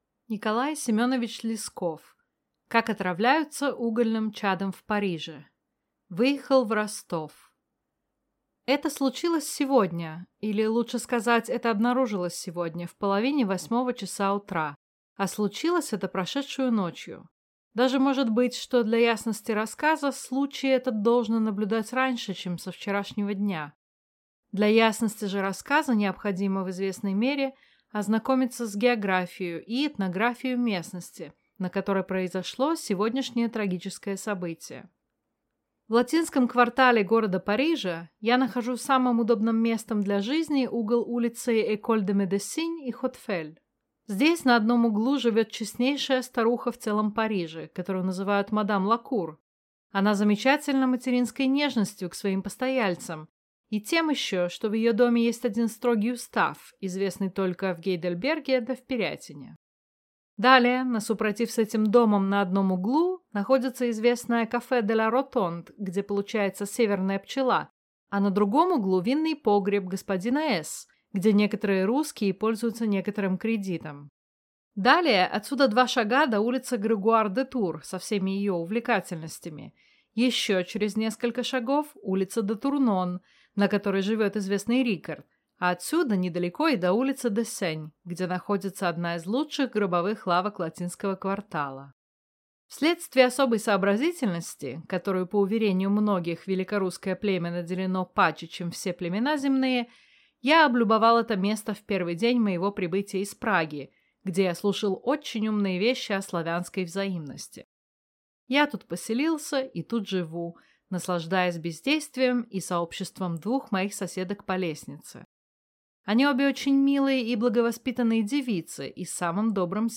Аудиокнига Как отравляются угольным чадом в Париже | Библиотека аудиокниг